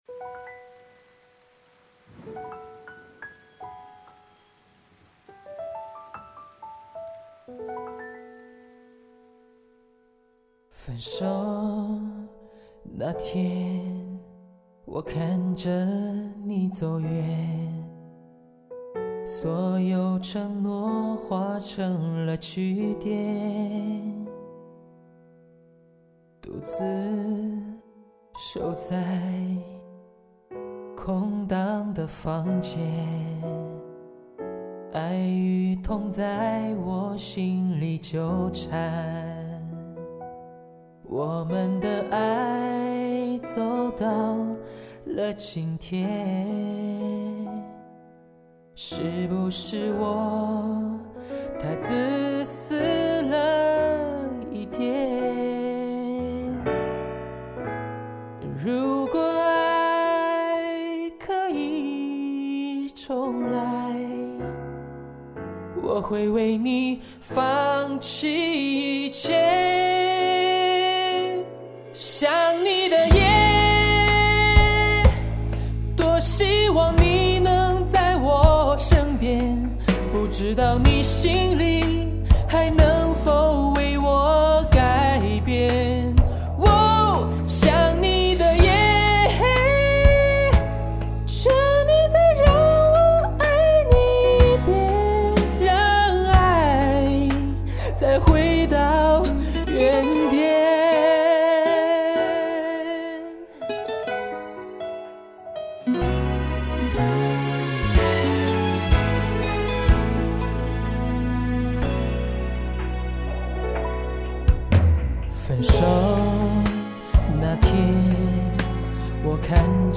最近要考试了，没时间架设备，就用USB话筒录了一个， 结果话筒悲剧的又被吼爆了一次。。。
这歌很发泄:lol:
声音很棒，唱的很好